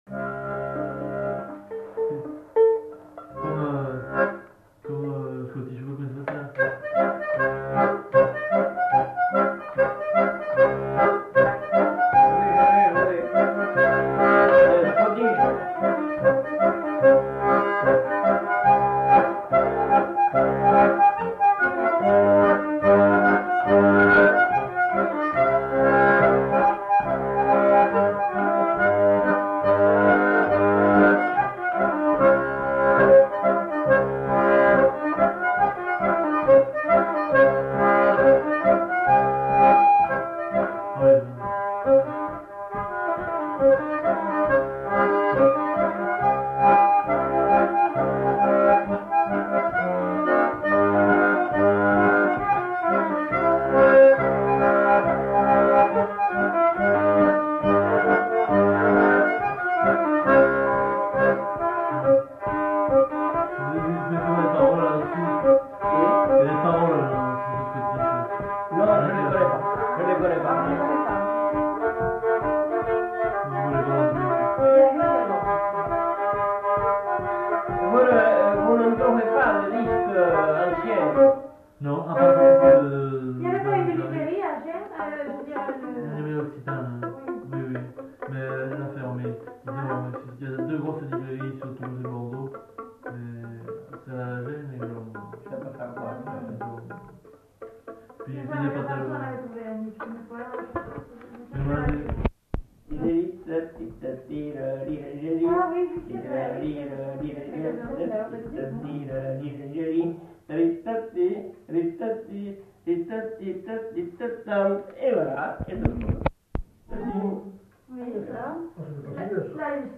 Aire culturelle : Néracais
Lieu : Sainte-Maure-de-Peyriac
Genre : morceau instrumental
Instrument de musique : accordéon diatonique ; violon
Danse : scottish